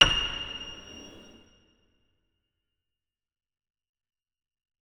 46265b6fcc Divergent / mods / Hideout Furniture / gamedata / sounds / interface / keyboard / piano / notes-77.ogg 64 KiB (Stored with Git LFS) Raw History